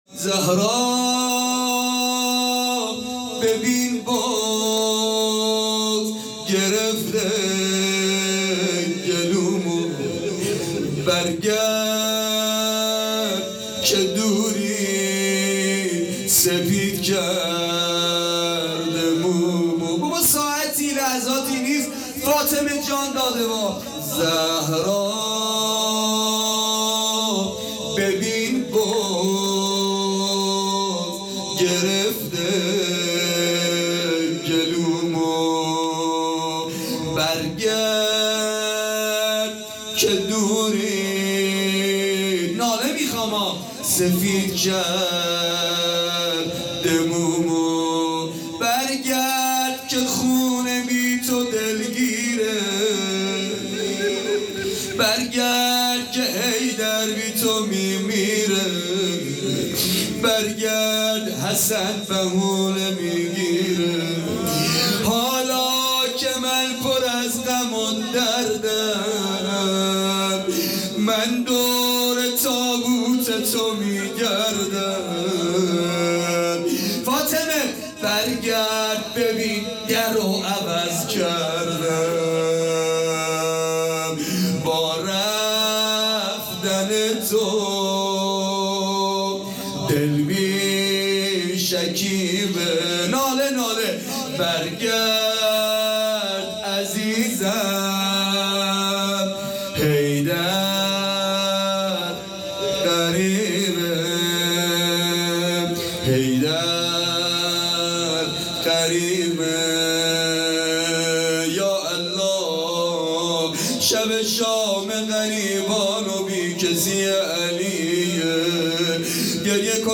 فاطمیه دوم ۹۷